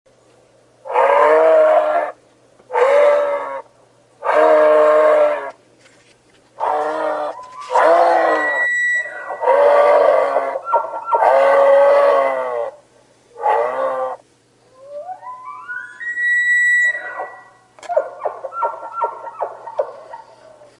Elch Geräusch - Wildtiere in Deutschland
Elch Geräusche
Männliche Elche stoßen tiefe, röhrende Laute aus, um Weibchen anzulocken und Rivalen abzuwehren. Weibchen geben häufig hohe, klagende Rufe von sich, um mit ihren Kälbern oder Männchen zu kommunizieren.
Elch-Geraeusch-Wildtiere-in-Deutschland.mp3